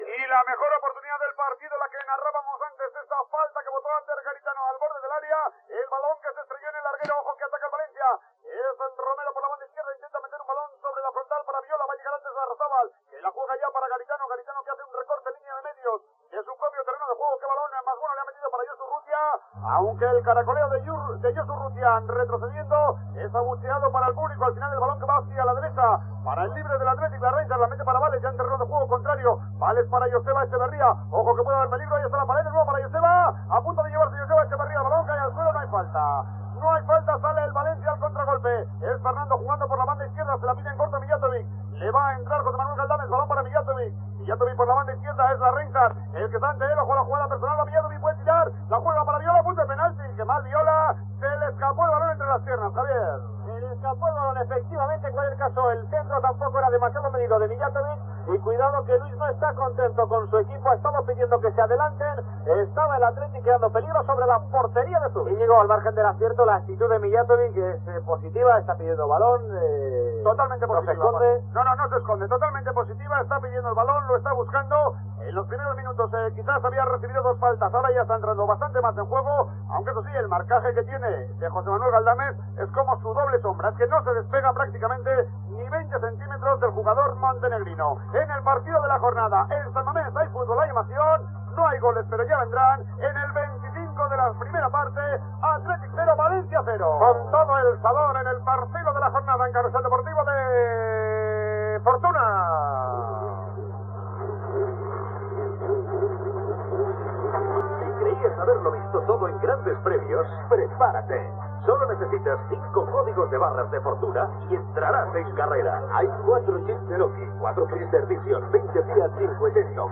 "Partido de la jornada" amb la narració del partit Athletic Club - València, publicitat, roda informativa dels resultats
Gènere radiofònic Esportiu